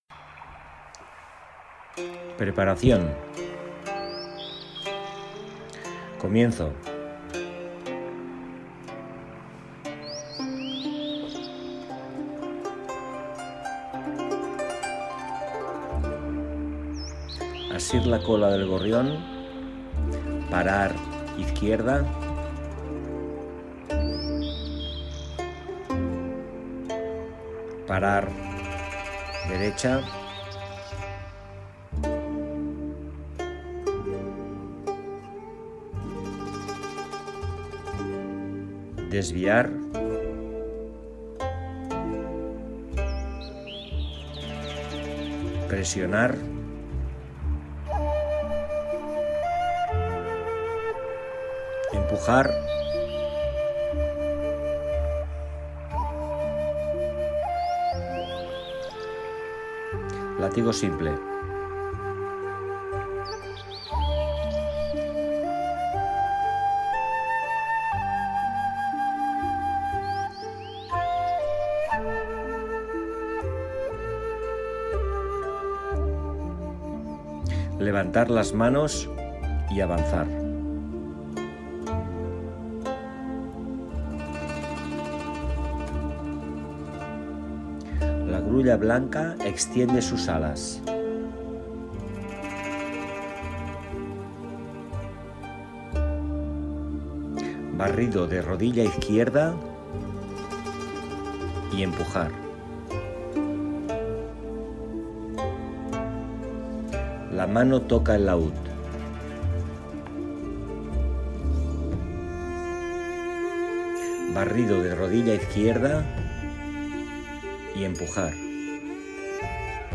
forma_sencera_cast_amb_musica.mp3